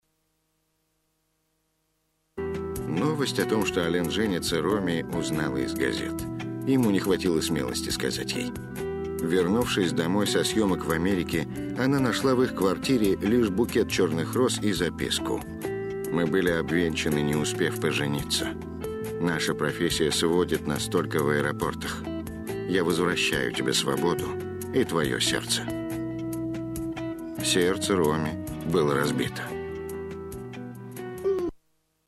Хотя под звук голоса эта мелодия и не так явно слышна.
инструментальный вариант песни